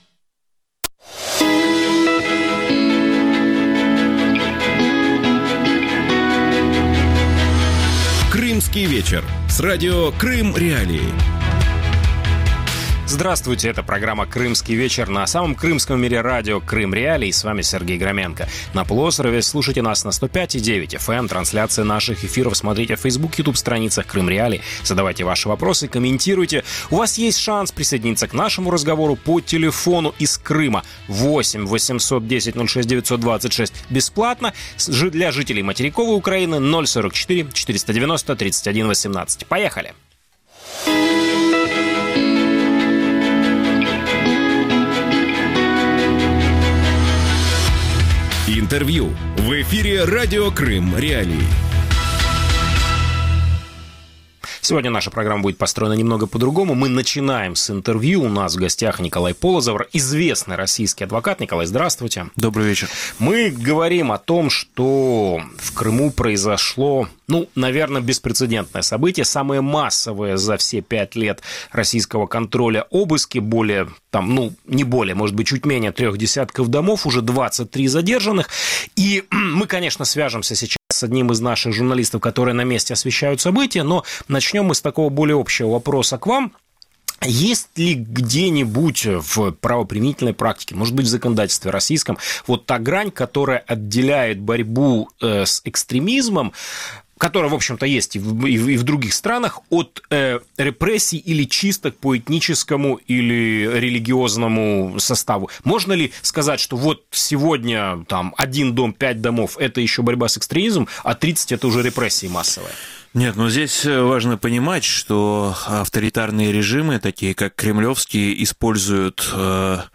«Крымский вечер» – ток-шоу, которое выходит в эфир на Радио Крым.Реалии в будни с 19.30 до 20.30 (18:30 – 19:30 по киевскому времени).